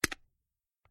Звук а теперь мы нажали на другую кнопку